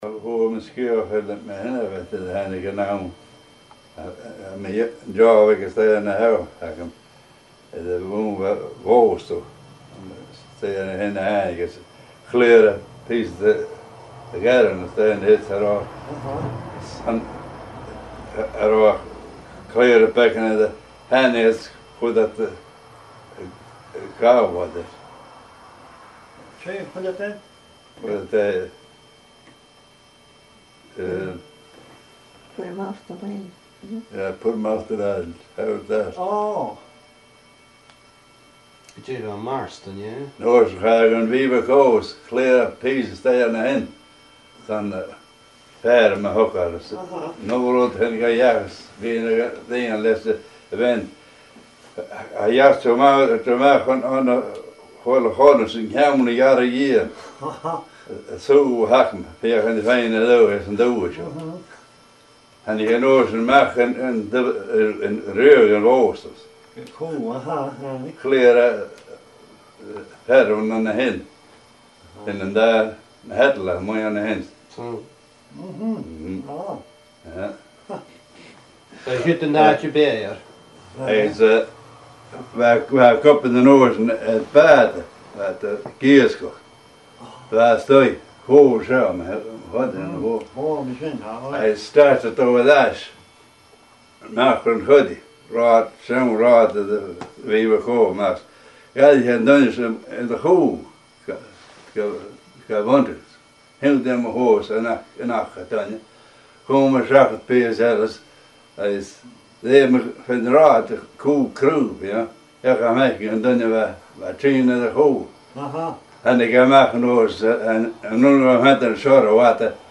An Neach-agallaimh